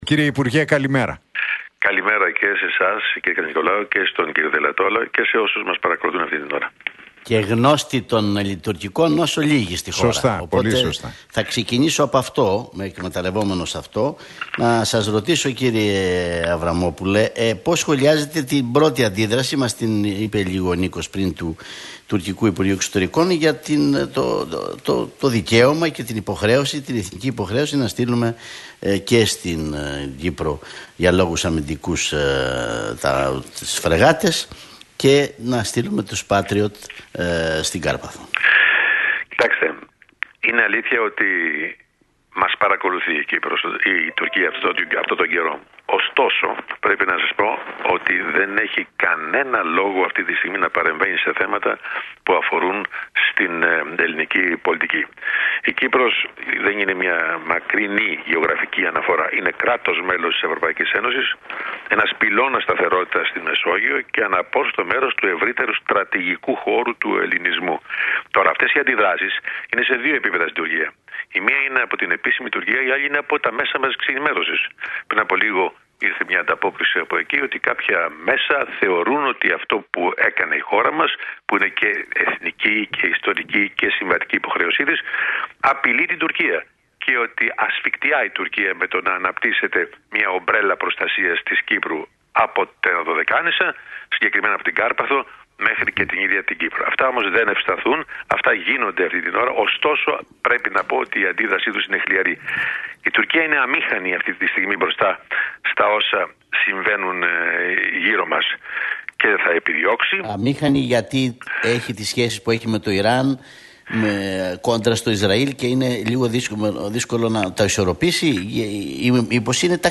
Αβραμόπουλος στον Realfm 97,8: Αμήχανη η Τουρκία αυτή τη στιγμή - Μας παρακολουθεί, αλλά δεν μπορεί να κάνει τίποτα παραπάνω